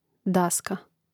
dàska daska